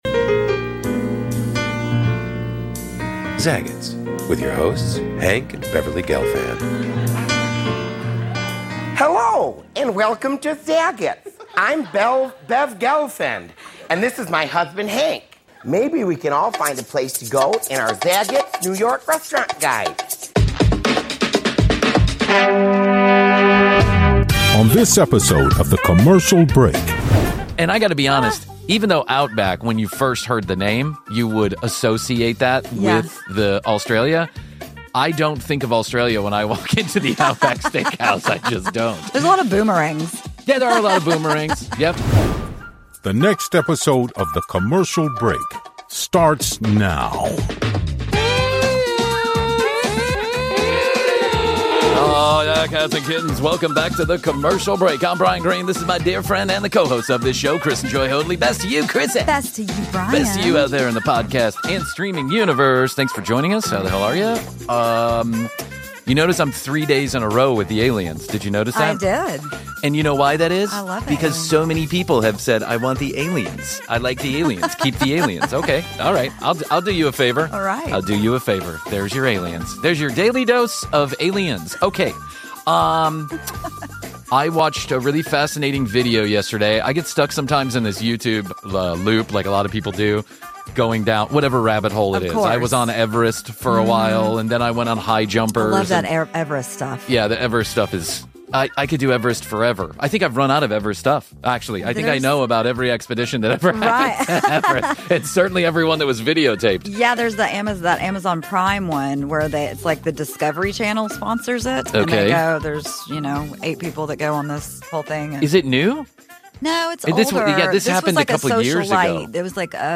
continue to work out the kinks for the live streamed episodes. This episode they discuss the tough business of restaurants, Outback Steakhouse and the decline of Aussie themed eateries and bad reviews of worse places to eat!